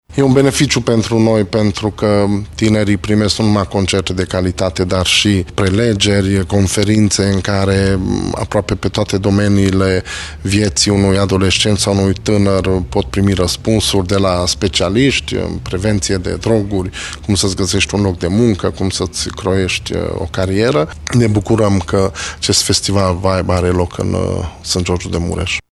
Pe lângă distracție, la Vibe fest participanții au posibilitatea să și învețe de la specialiști, a subliniat Sófalvi Szabolcs, primarul localității Sângeorgiu de Mureș: